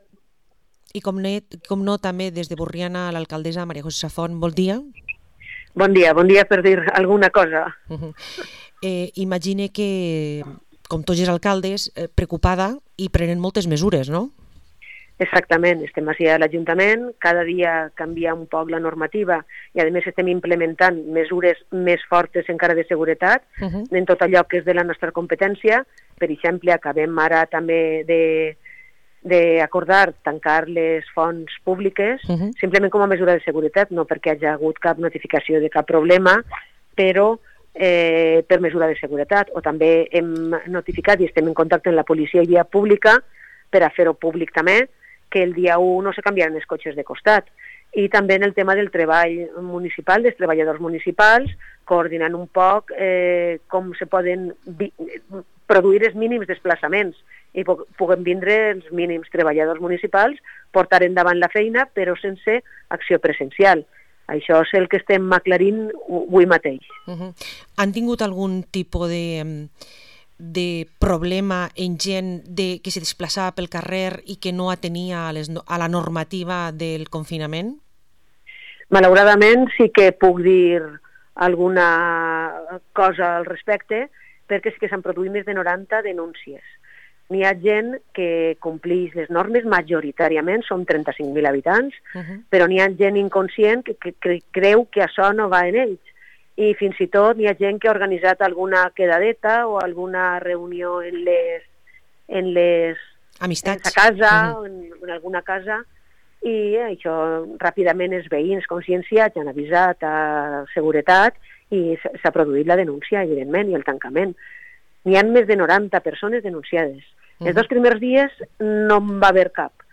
Entrevista a María José Safont, alcaldessa de Borriana